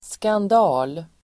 Uttal: [skand'a:l]